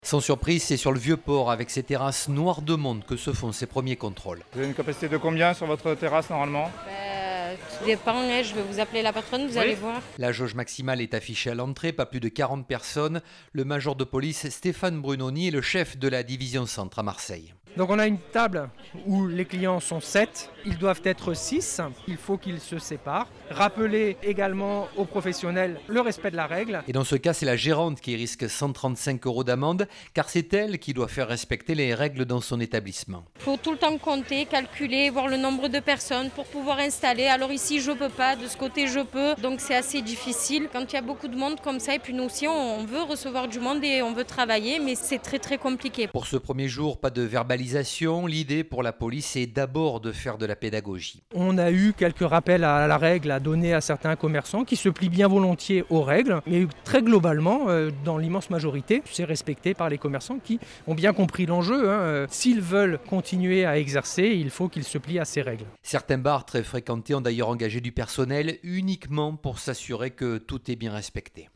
Sur le vieux port de Marseille, la police a fait de la pédagogie concernant les règles sanitaires et les jauges pour la réouverture.